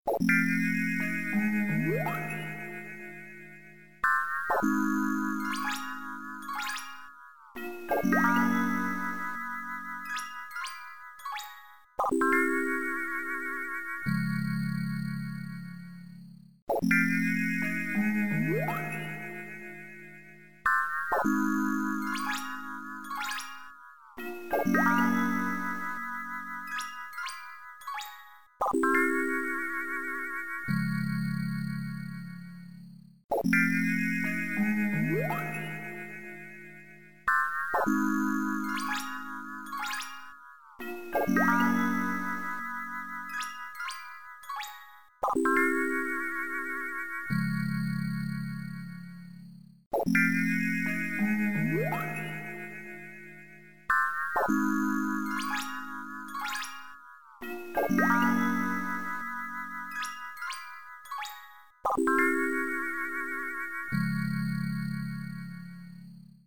at 120% seped